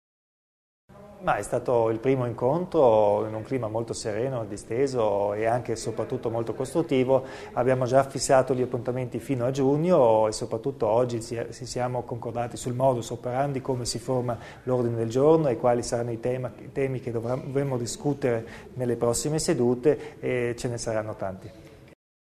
Il Presidente Kompatscher illustra l'esito del primo incontro Provincia-Comune